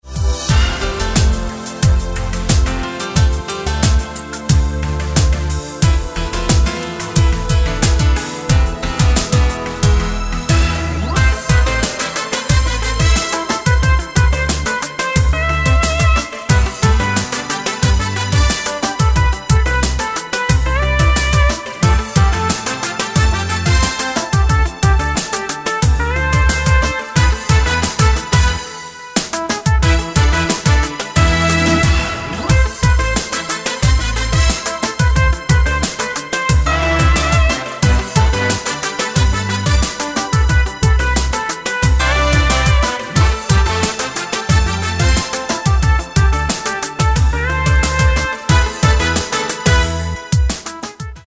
Фрагмент музыки к документальному фильму
Композиция, аранжировка, программирование, сведение.